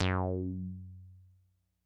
标签： midivelocity111 F4 midinote66 RolandJX8P synthesizer singlenote multisample
声道立体声